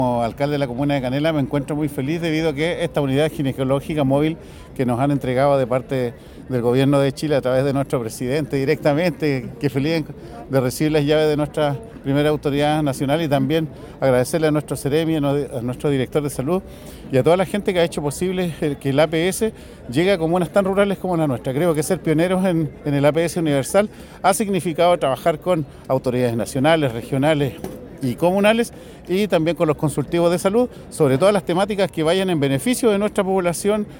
Este nuevo equipo móvil busca reducir brechas de acceso, fortaleciendo la oferta de salud existente en la comuna, avances que el alcalde de Canela, Waldo Contreras, destacó, señalando que
Waldo-Contreras-Alcalde-de-Canela-online-audio-converter.com_.mp3